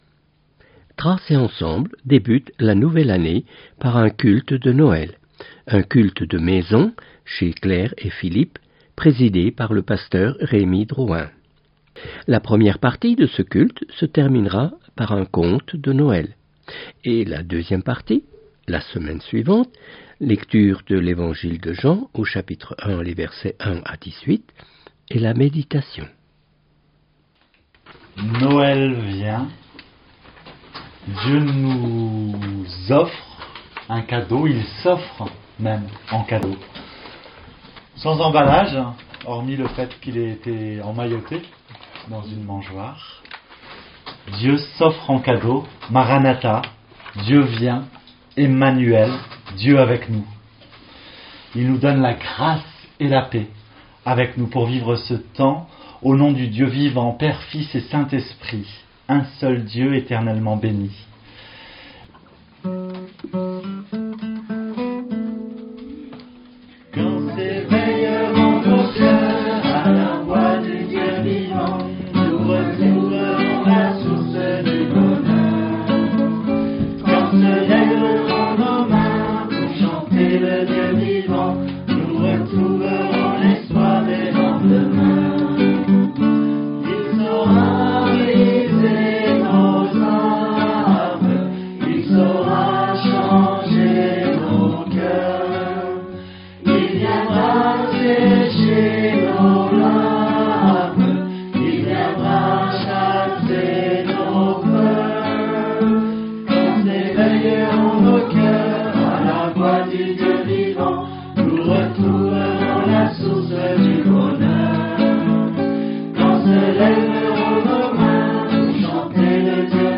Culte